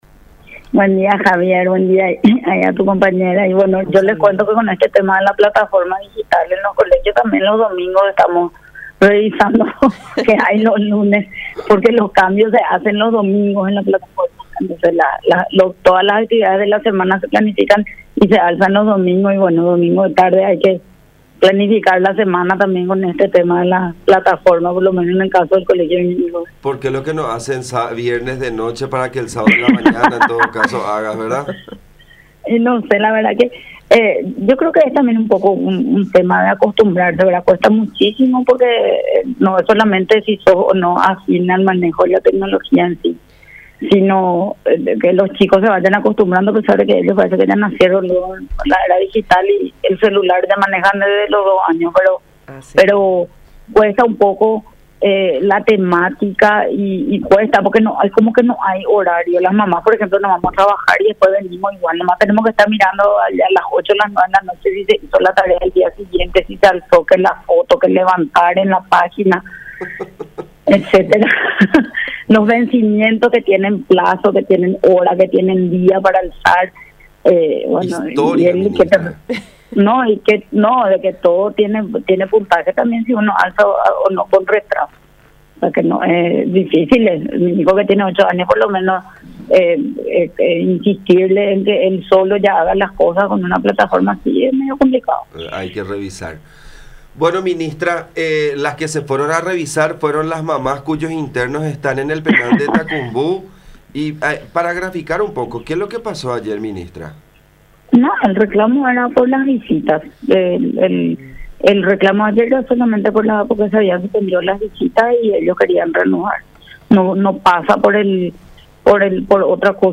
Los reclusos argumentan citando como ejemplo que más allá de la cancelación temporal de las visitas, de igual modo hace unas semanas el coronavirus había ingresado a la cárcel de Ciudad del Este mediante los agentes penitenciarios, según comentó la ministra de Justicia, Cecilia Pérez, en contacto con La Unión.